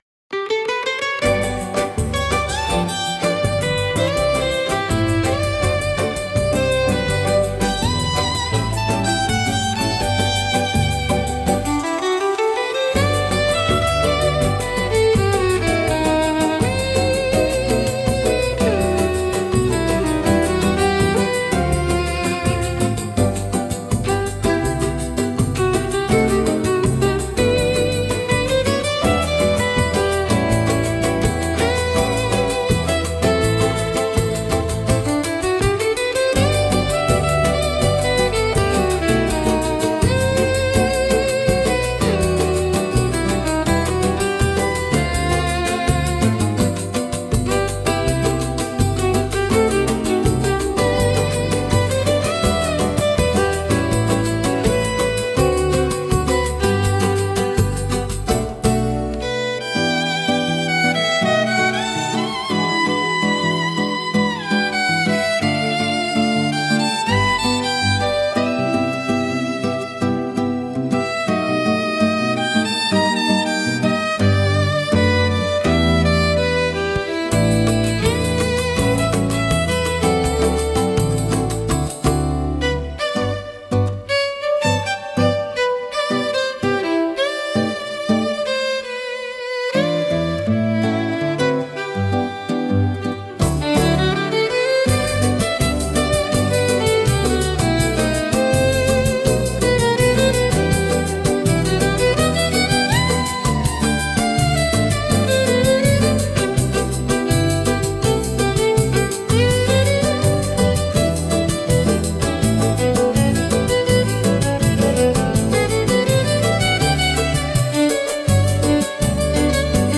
música, arranjo e voz: IA) (Instrumental)